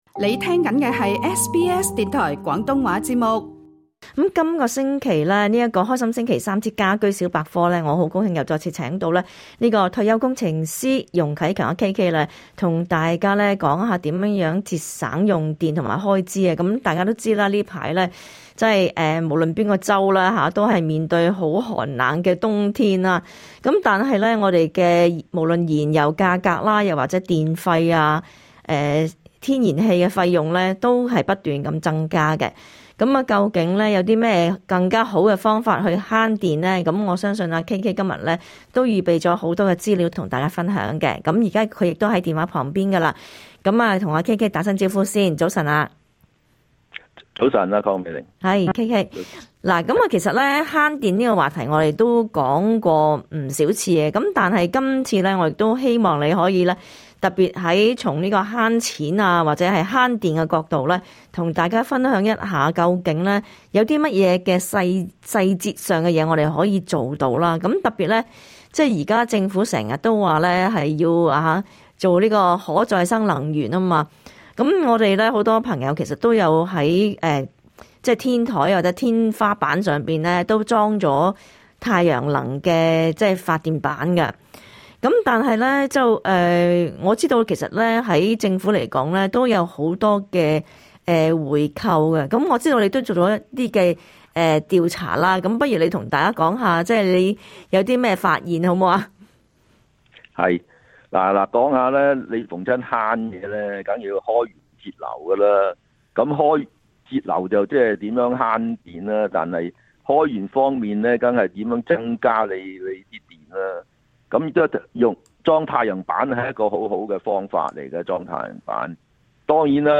他亦會解答聽眾有關安裝太陽能板的問題。